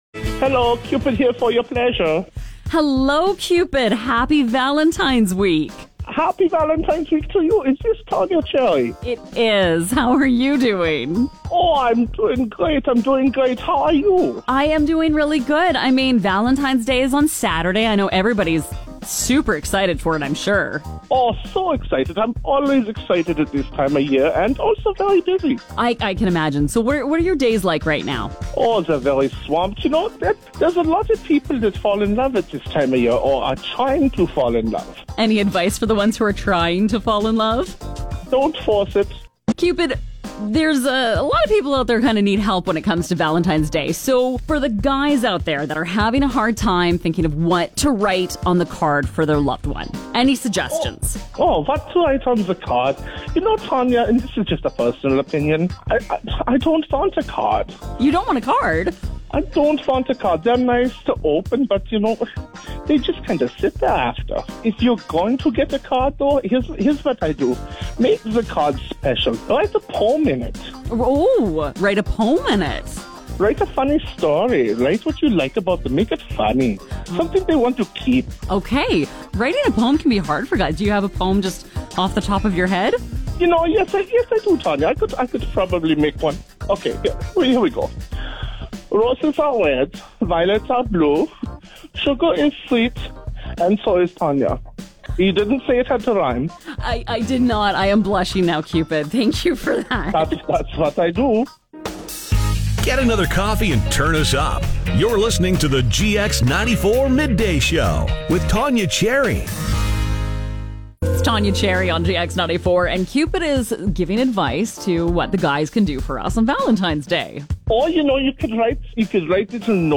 If you missed any of our on-air chats, don’t worry — I’ve got the audio ready for you.